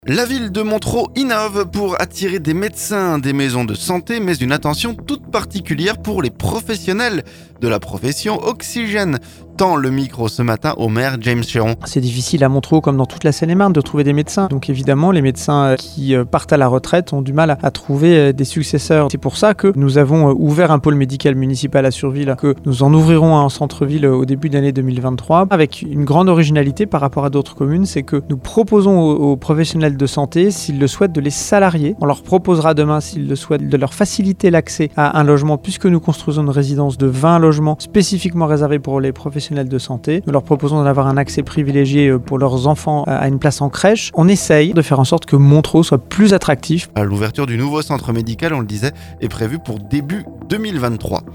Oxygène tend le micro ce mardi au maire James Chéron.